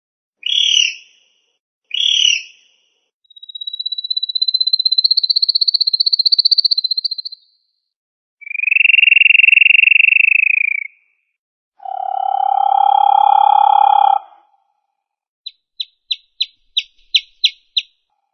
Naturljud, Android